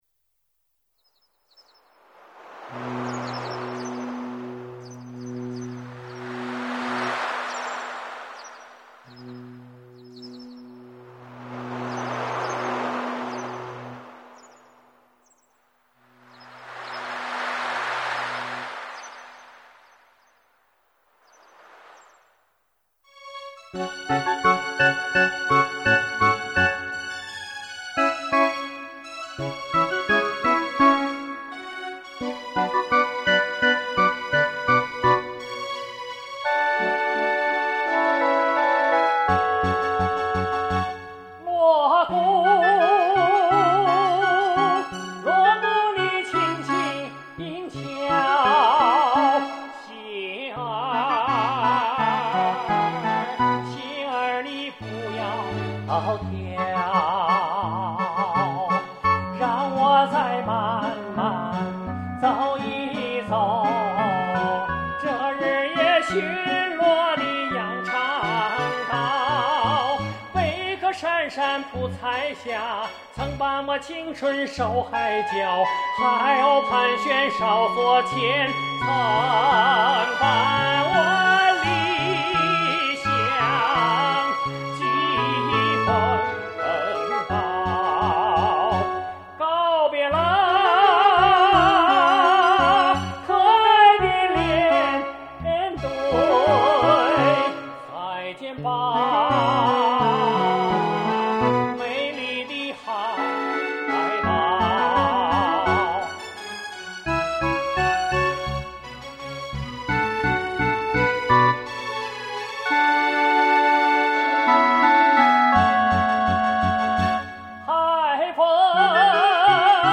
给你找到一首翻唱版的